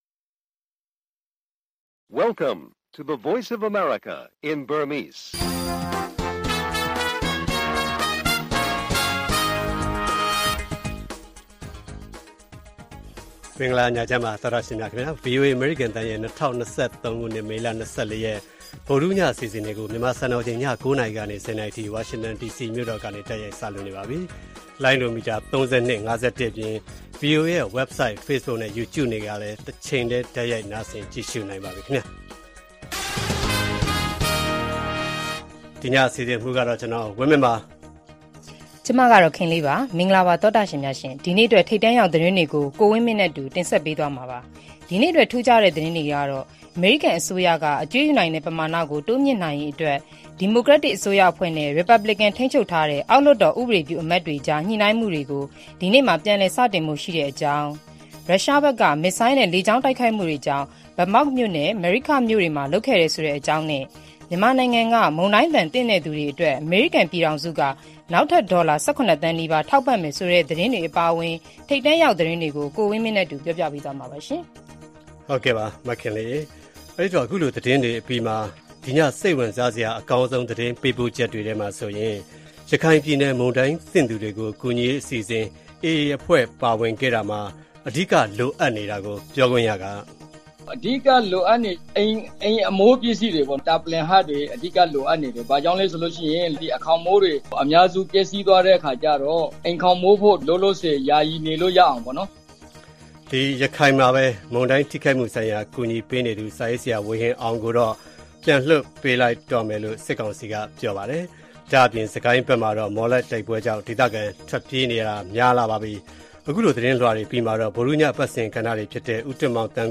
မုန်တိုင်းသင့် မြန်မာပြည်သူတွေအတွက် ဒေါ်လာ ၁၇ သန်း အမေရိကန် ထောက်ပံ့မည်၊ ရခိုင်ပြည်နယ် မုန်တိုင်းသင့်သူတွေ ကူညီရေး အစီအစဉ် AA အဖွဲ့ပါဝင် စတာတွေအပြင် အယ်ဒီတာနဲ့ ဆွေးနွေးခန်း၊ လူထုနဲ့ကျန်းမာရေး၊ အားကစား သီတင်းပတ်စဉ် ကဏ္ဍတွေလည်း တင်ဆက်ထားပါတယ်။